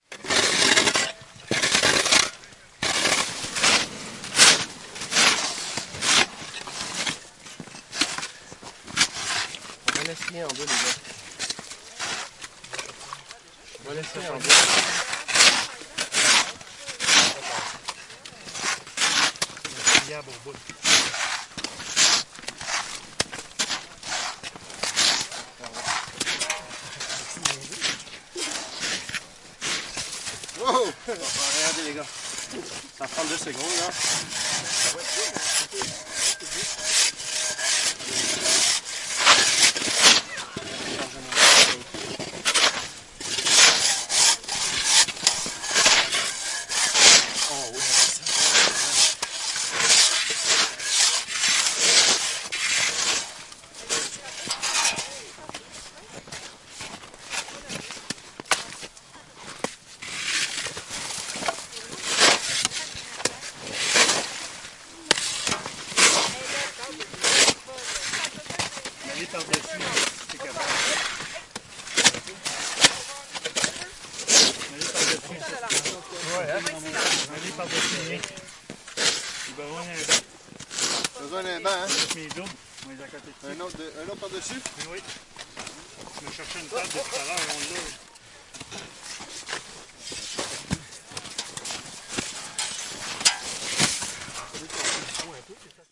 随机 " 加拿大渥太华麦克风周围铲雪和锯冰的团体冰上潜水员
描述：小组冰潜水员铲雪和锯冰在mic渥太华，Canada.flac附近
Tag: 潜水员